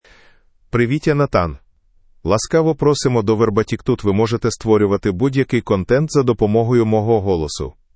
Nathan — Male Ukrainian AI voice
Nathan is a male AI voice for Ukrainian (Ukraine).
Voice sample
Male
Nathan delivers clear pronunciation with authentic Ukraine Ukrainian intonation, making your content sound professionally produced.